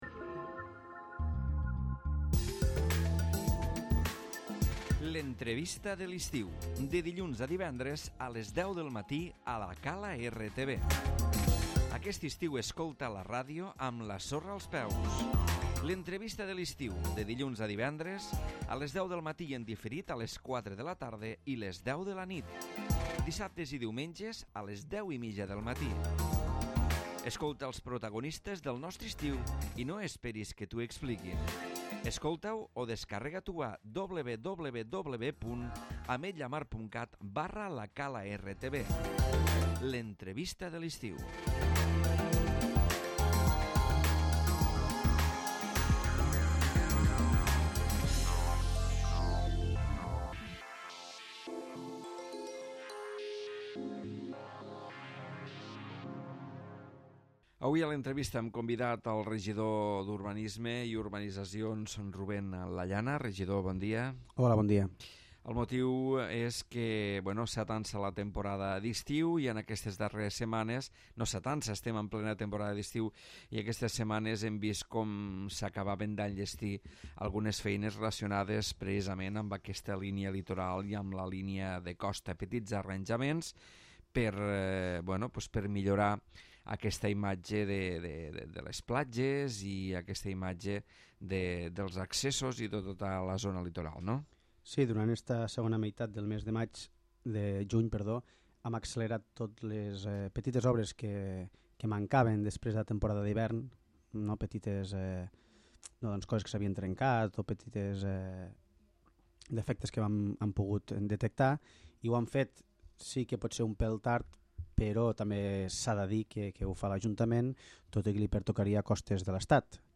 L'Entrevista
Rubén Lallana, regidor de Territori i Paisatge i de relacions amb les urbanitzacions parla de petites millores realitzades a les platges, accessos i zones properes a la costa.